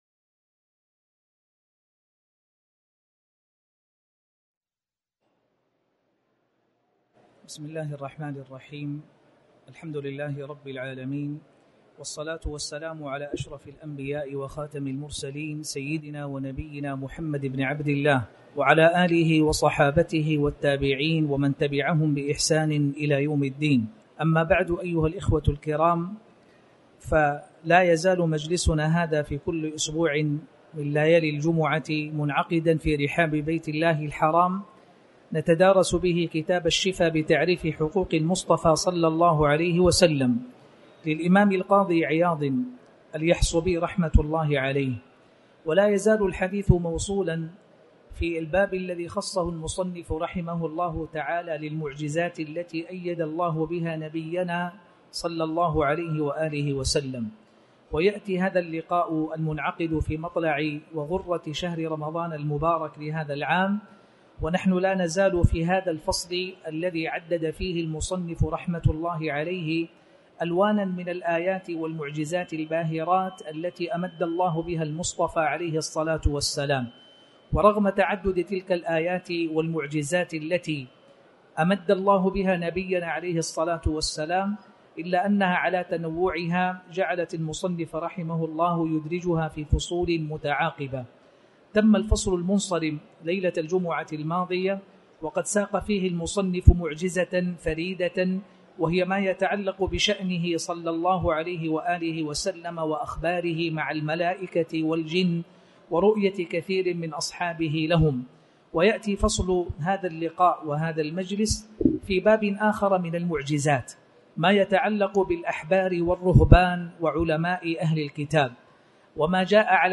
تاريخ النشر ١ رمضان ١٤٣٩ هـ المكان: المسجد الحرام الشيخ